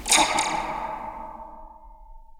zap4_v1.wav